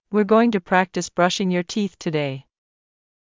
ｳｨｰｱｰ ｺﾞｰｲﾝｸﾞ ﾄｩ ﾌﾟﾗｸﾃｨｽ ﾌﾞﾗｯｼﾝｸﾞ ﾕｱ ﾃｨｰｽ ﾄｩﾃﾞｲ